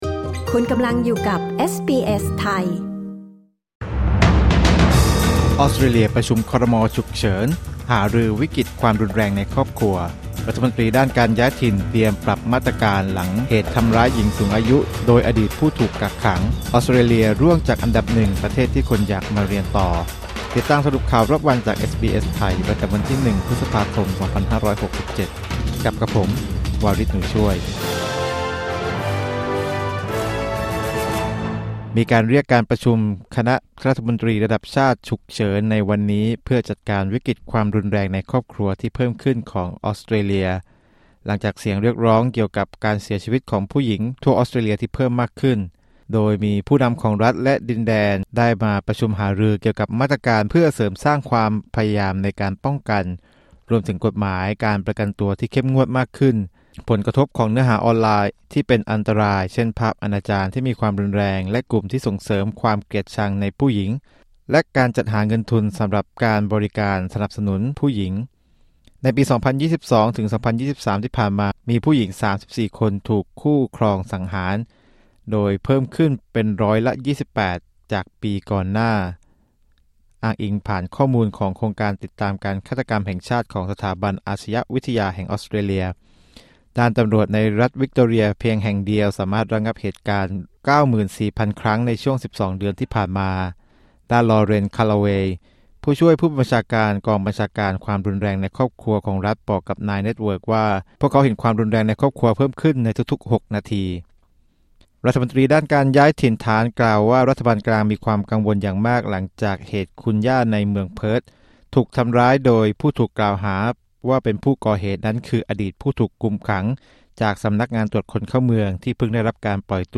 สรุปข่าวรอบวัน 01 พฤษภาคม 2567